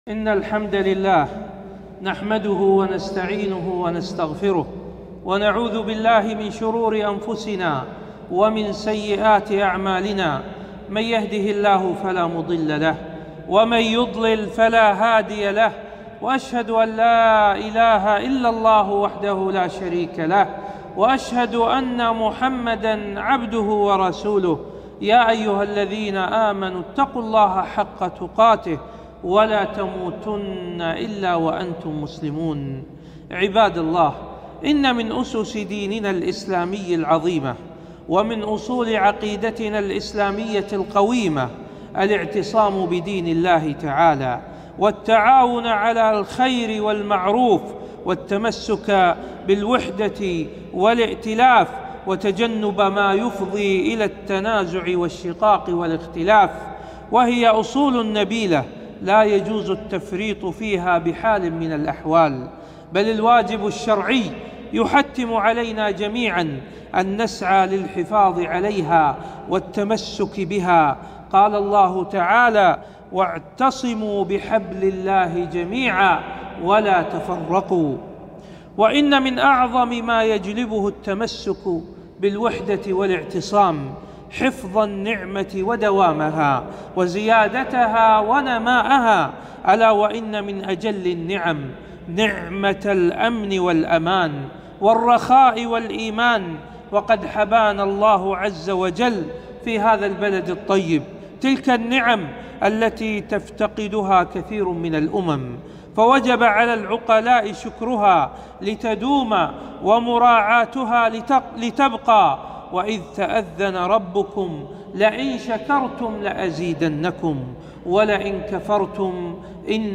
خطبة - الموقف الشرعي عند الفتن